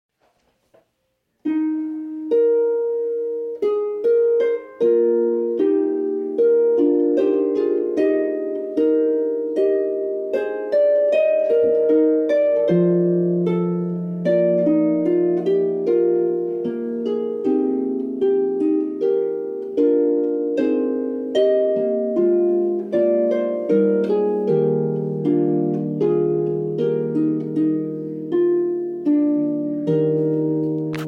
solo pedal harp